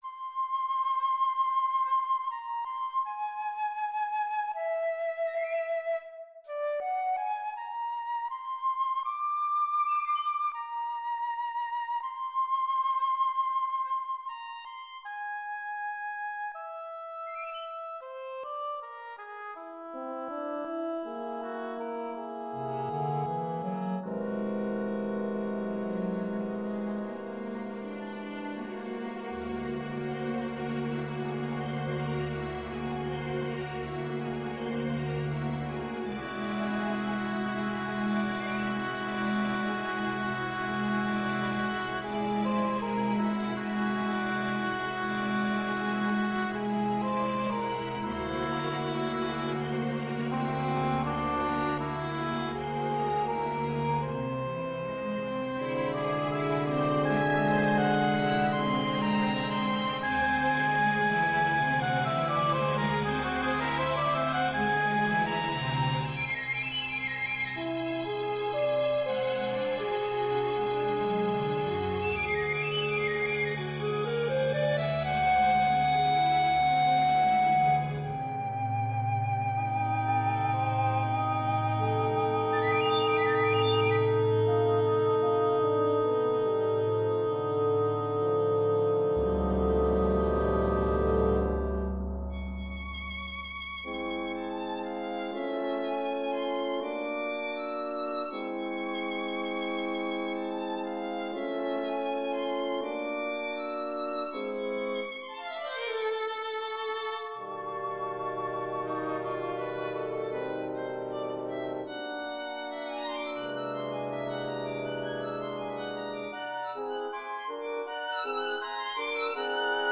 Trompettes 1 & 2 (utilisation de sourdines)
Trombone (utilisation de sourdines)
Percussions 1 : Timbales + accessoires